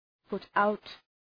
put-out.mp3